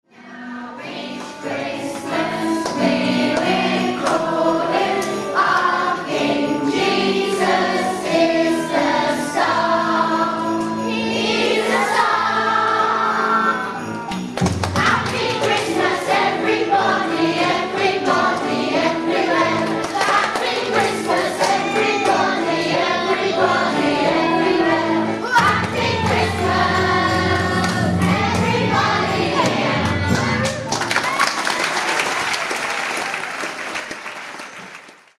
a live performance